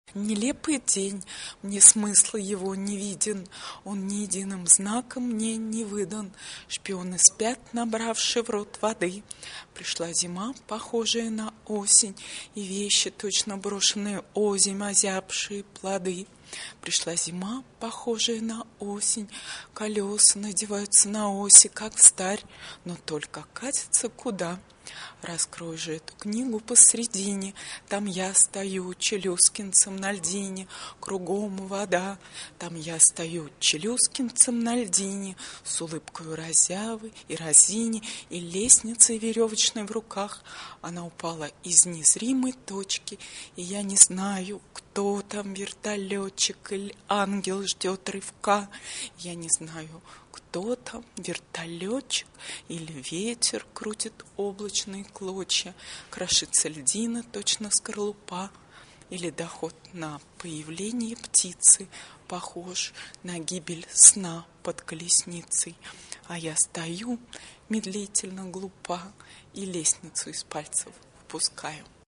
read by poet